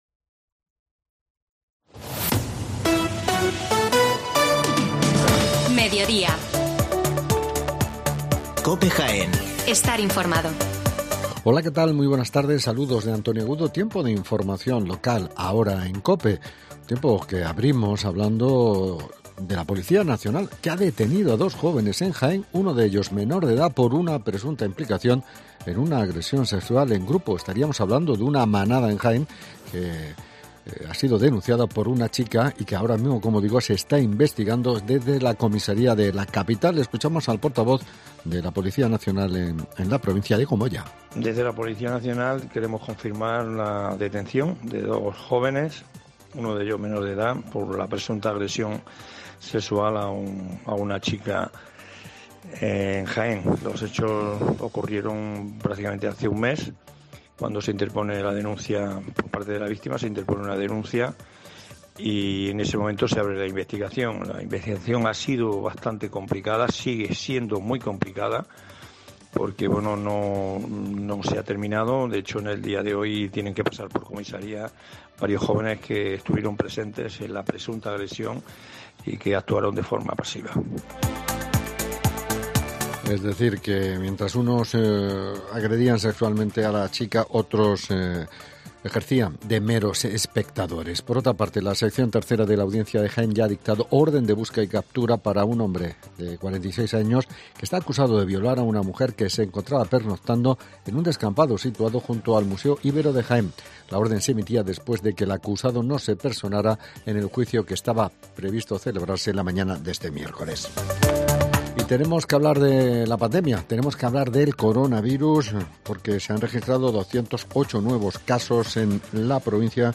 Las noticias locales